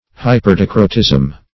Hyperdicrotism \Hy`per*di"cro*tism\, n.